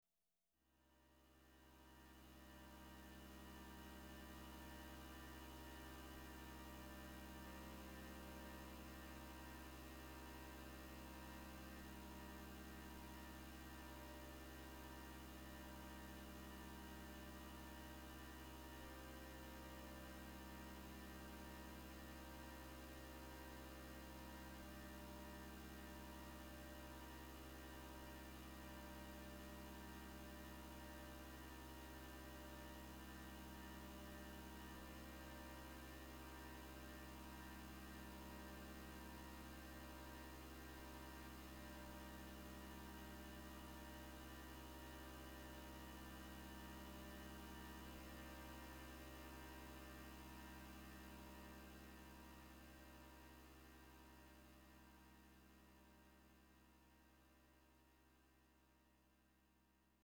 experimental music